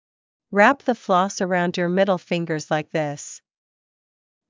ﾗｯﾌﾟ ｻﾞ ﾌﾛｽ ｱﾗｳﾝﾄﾞ ﾕｱ ﾐﾄﾞﾙ ﾌｨﾝｶﾞｰｽﾞ ﾗｲｸ ﾃﾞｨｽ